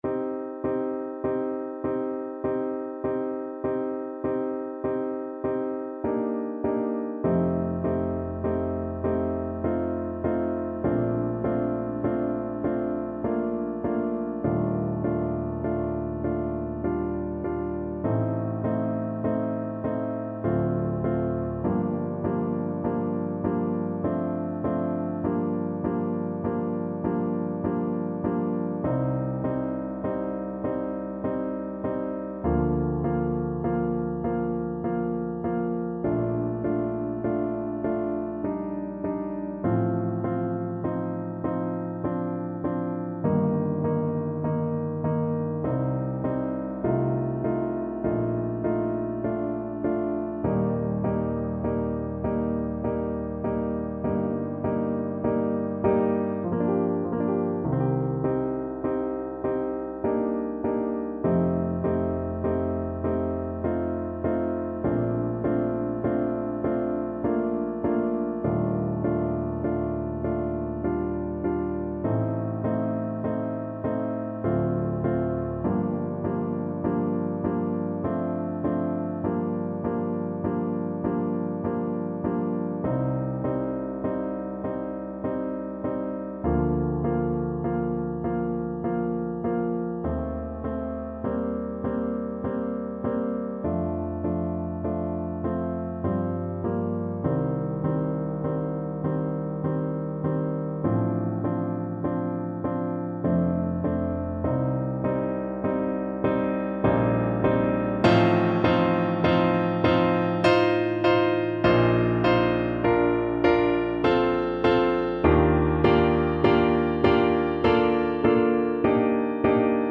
Bb major (Sounding Pitch) (View more Bb major Music for Oboe )
3/4 (View more 3/4 Music)
Andantino = 50 (View more music marked Andantino)
Classical (View more Classical Oboe Music)